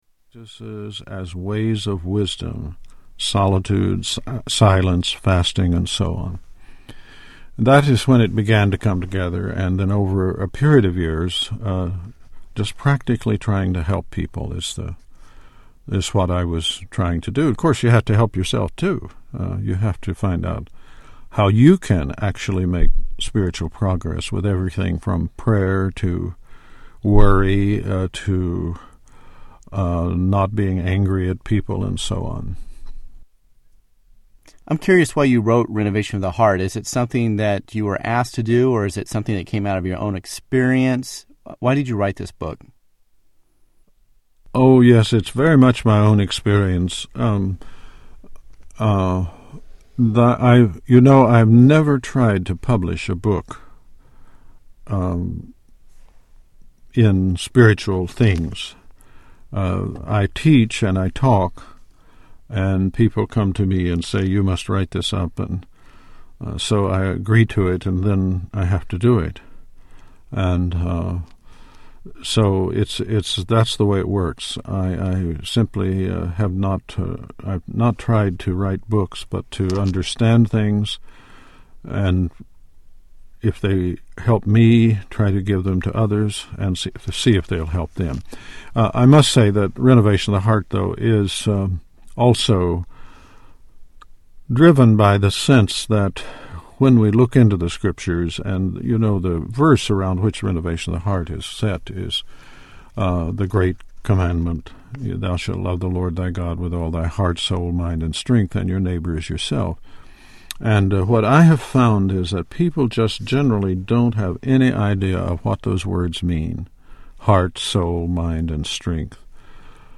Tags: Media Writer Christian Christian audio books Audio books